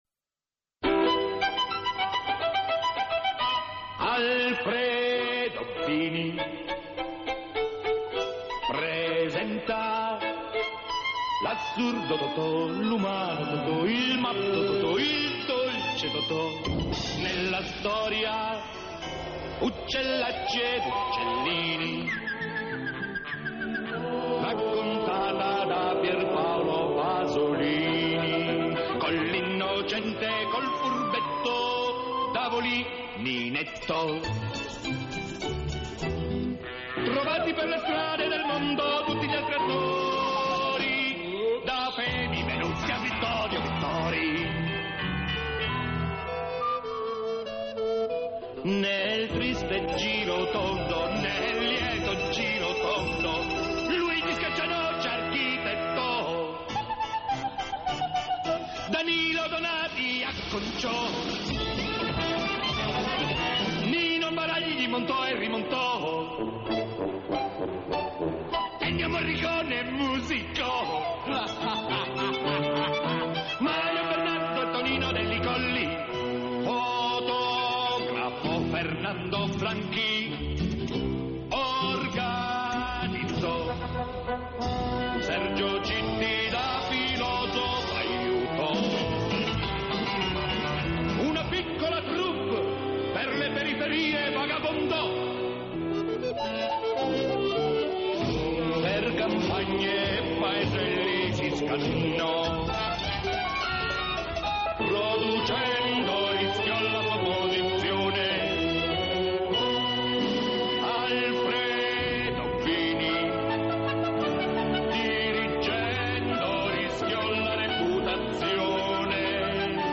اجرای زنده ارکسترال